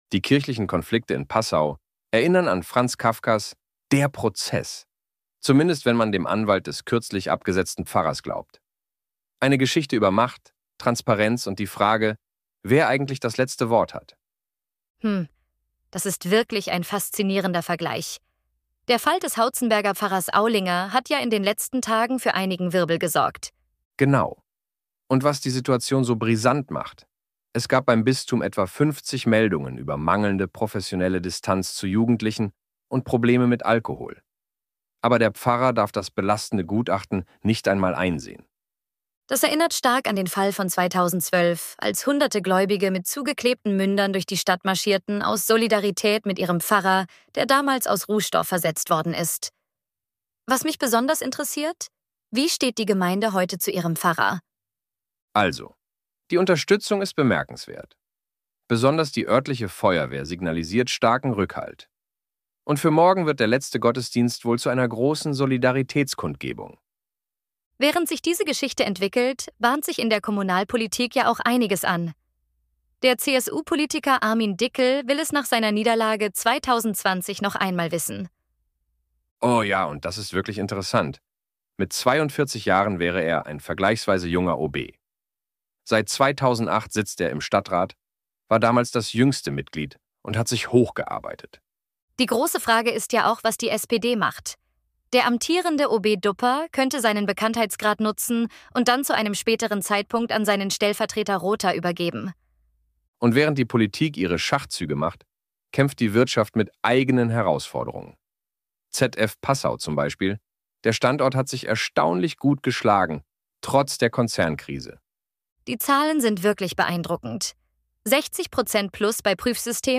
Unser Nachrichtenticker als KI-Kost: „Dialog aus der Dose“ –